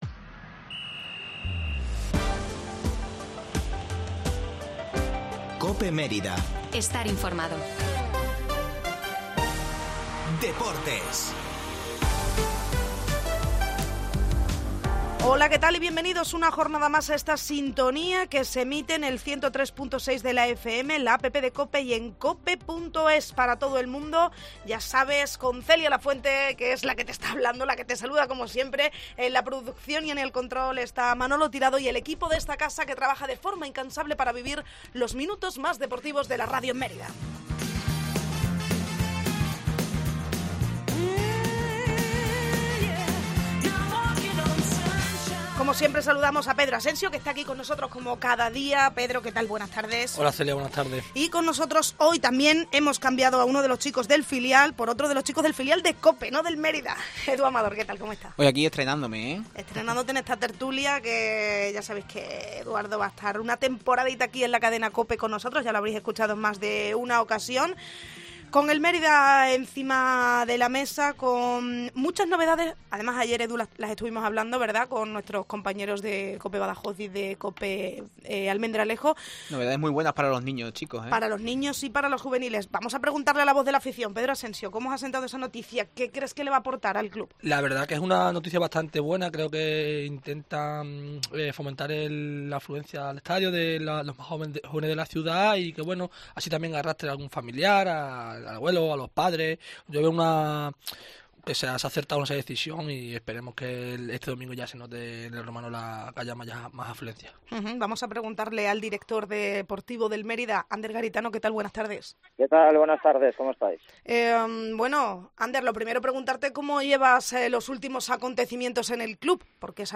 Tertulia del Mérida en COPE